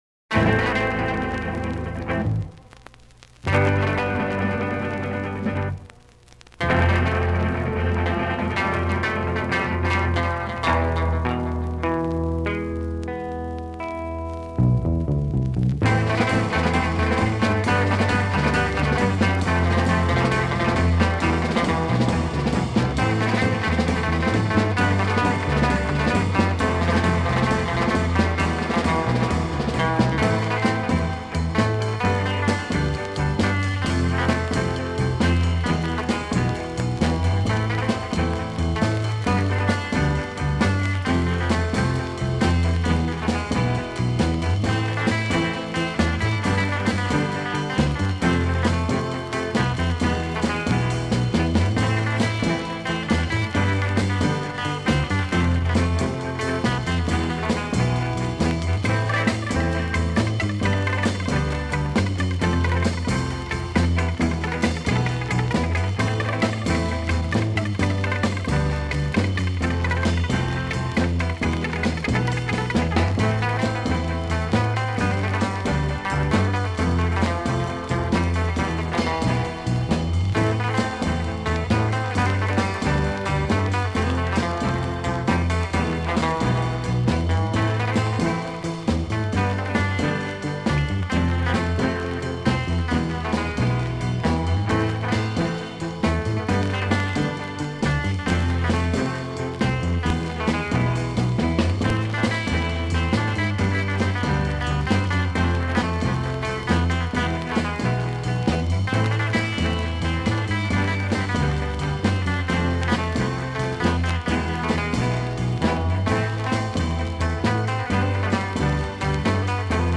lead guitar
rhythm guitar
bass
drums